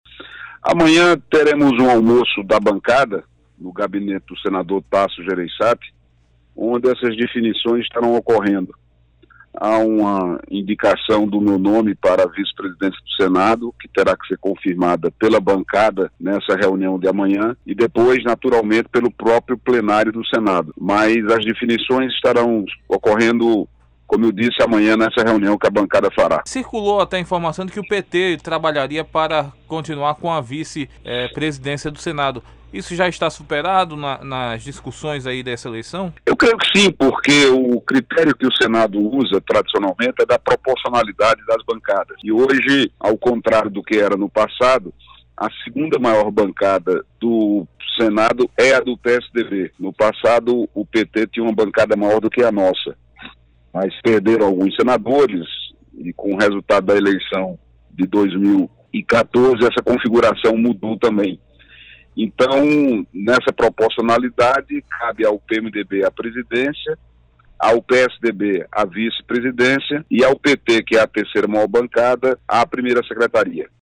Um almoço da bancada tucana, nesta quarta (1), vai selar a indicação do senador paraibano Cássio Cunha Lima para ocupar a vice-presidência do Senado indicado pelo PSDB. O senador contou como andam as articulações e afirmou que o PT deve ocupar outro espaço na Mesa.